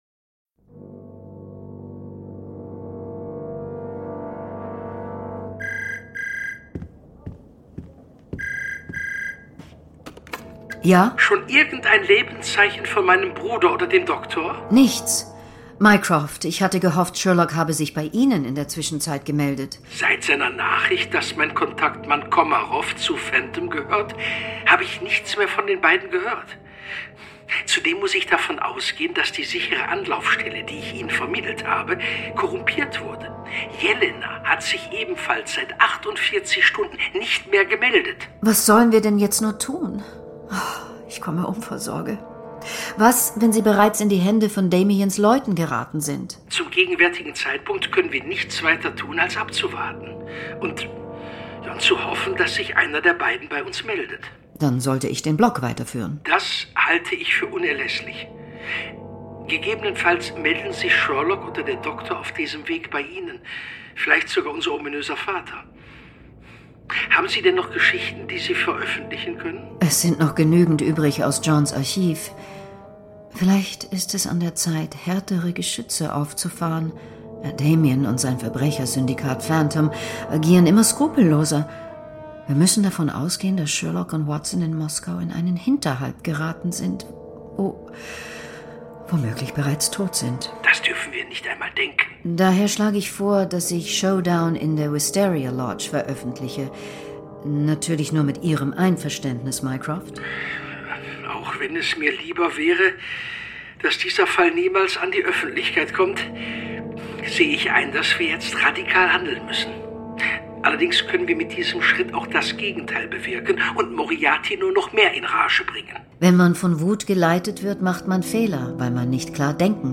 Johann von Bülow, Florian Lukas, Peter Jordan (Sprecher)
Hörspiel mit Johann von Bülow, Florian Lukas, Peter Jordan